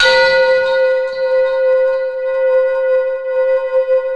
POWERBELL C5.wav